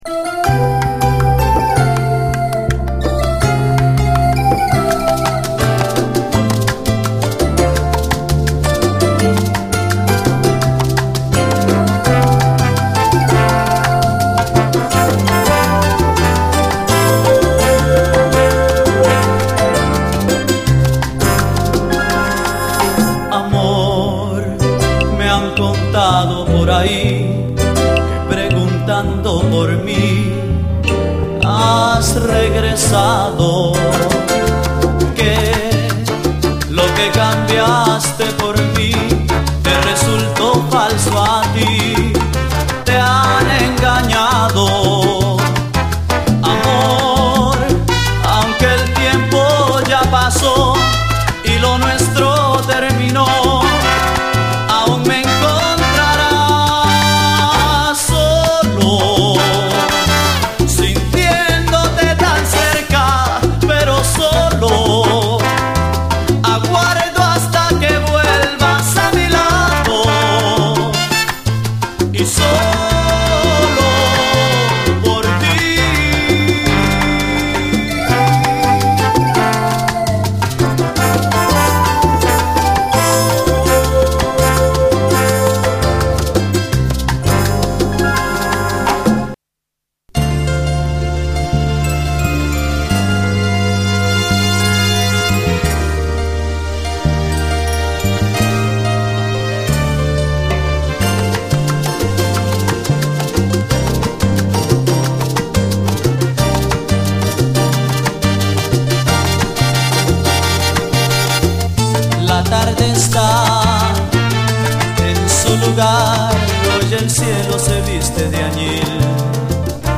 鋭いレアグルーヴ感で切り裂くスリリング・モダン・ソウル
トロピカルなブレイクからレゲエ調な展開を挟むというなかなか珍しいタイプの曲で当店好みなミディアム・ソウル